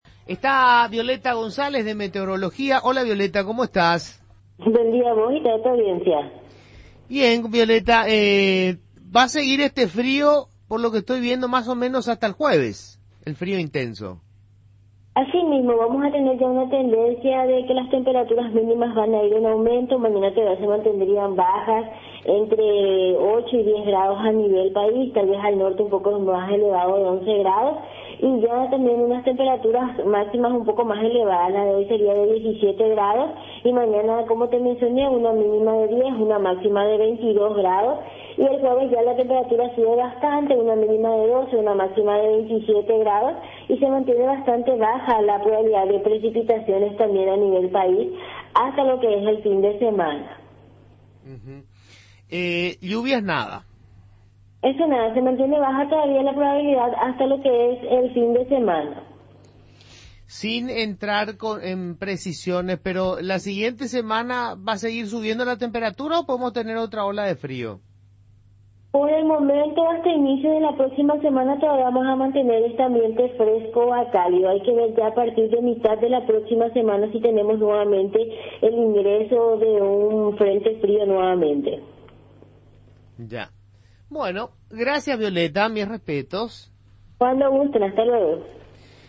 pronosticadora de turno